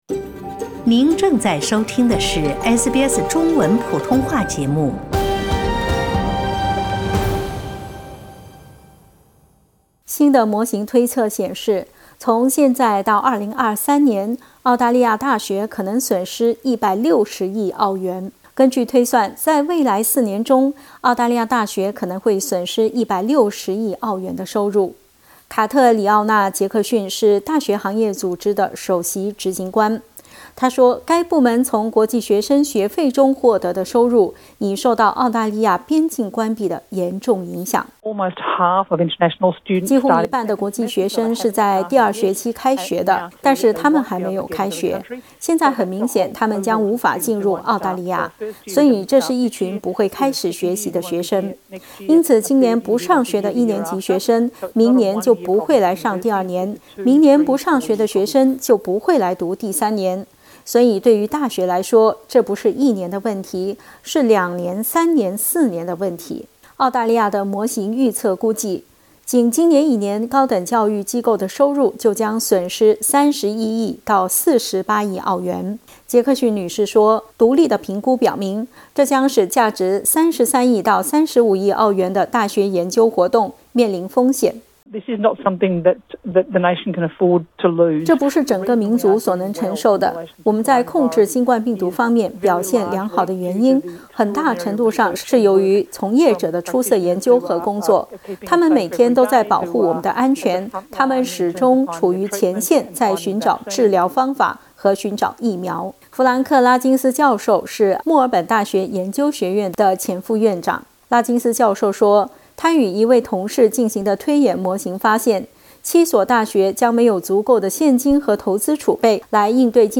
新的模型预测显示，从现在到2023年，澳大利亚大学可能损失160亿澳元。 点击图片收听详细报道。